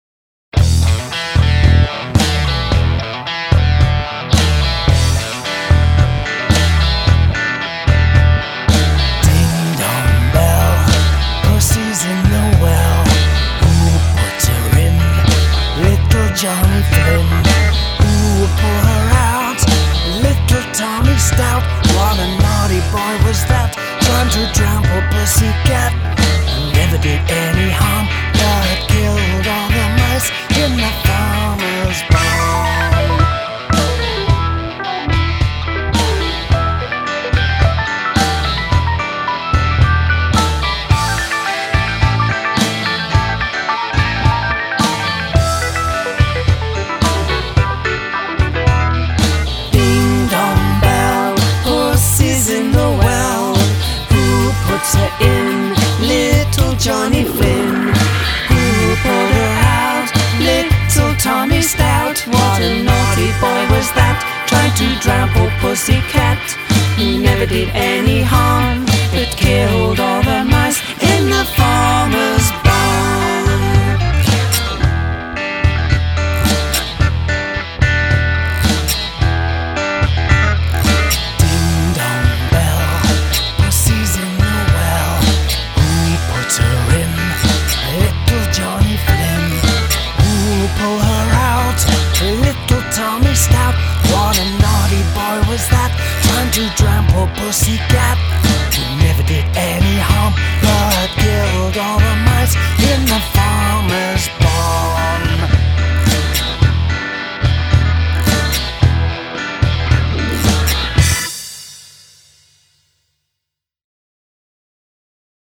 SpookyFun & Punk